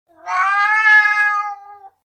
고양이(놀아~)2a
cat2a.mp3